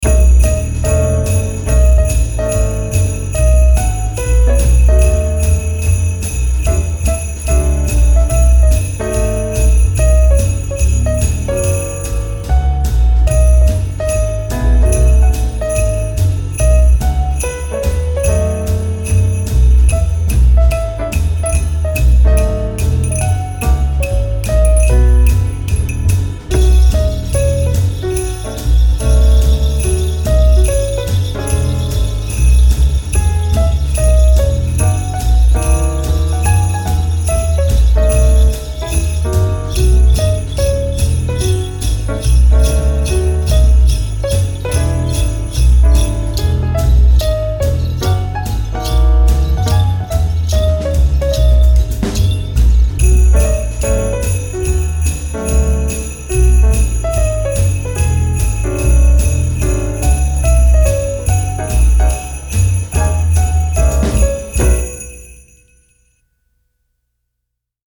流行音乐与特效
借助这套融合了稀有打击乐与氛围音效的混音素材，为您的商业音轨和配乐增添独特韵味。
包含乐器： 雪橇与阿朱奇铃、流行打击乐混音、工业金属打击乐、海洋鼓和驴颚骨。
Sleigh___Ajuch_Bells_Demo.mp3